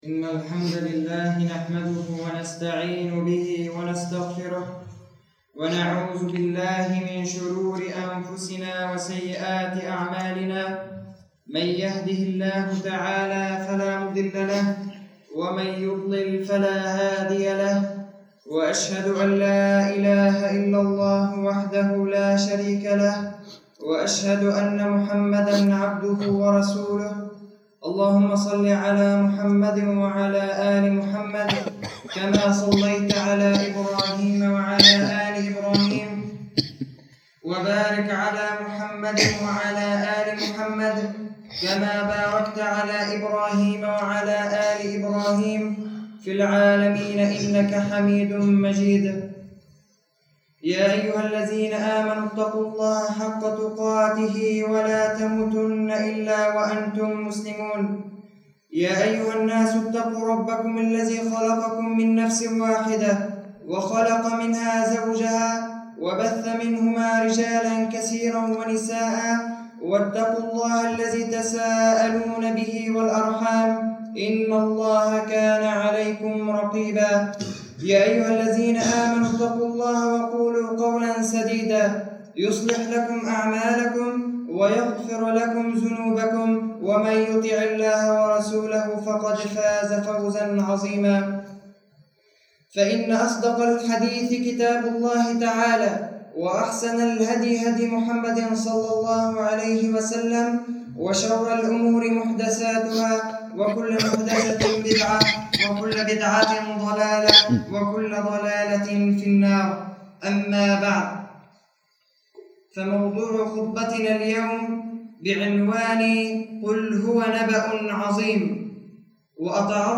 [خطبة جمعة] قل هو نبأٌ عظيم